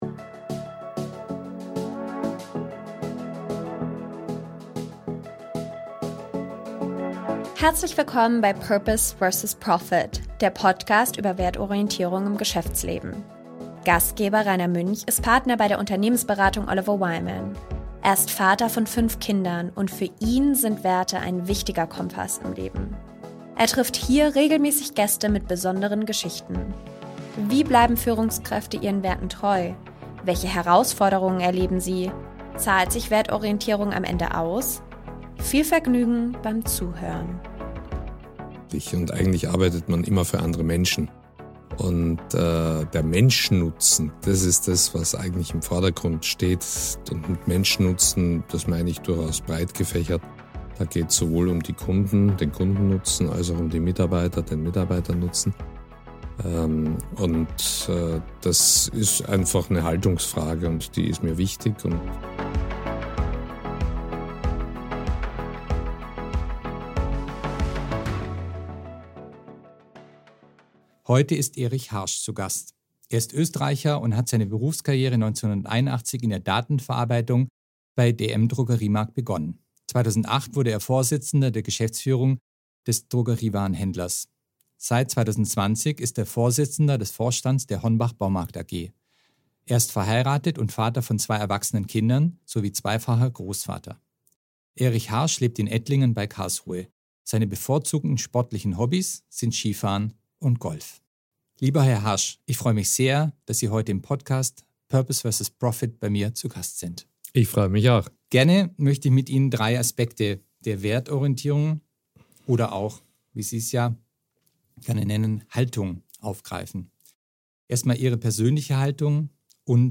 Betrachtungen von Philosophen kommen auch nicht zu kurz. Das Gespräch wurde aufgezeichnet im Mai 2024.